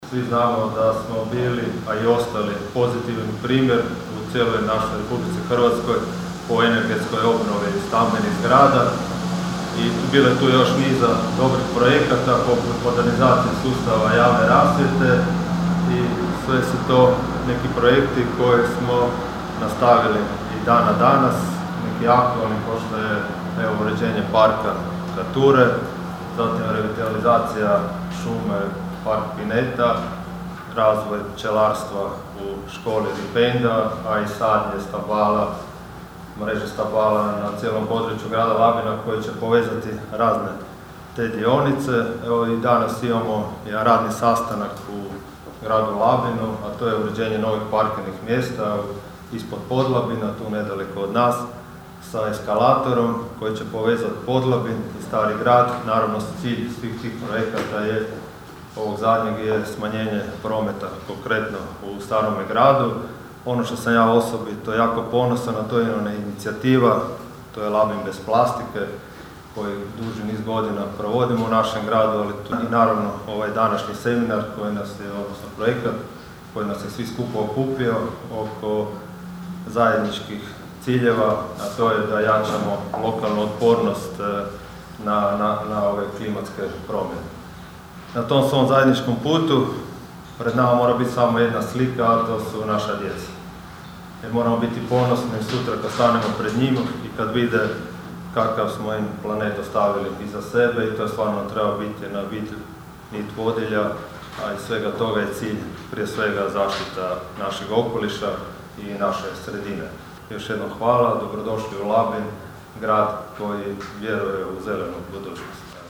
Grad Labin danas je domaćin stručnog seminara „Klimatska otpornost zajednice – lokalni pristupi i primjeri dobre prakse“, koji se održava u sklopu europskog projekta “Road 2 Resilience” (R2R).
Sudionike seminara pozdravio je labinski gradonačelnik Donald Blašković, kazavši kako se Labin odavno opredijelio za zelenu politiku i održivi razvoj: (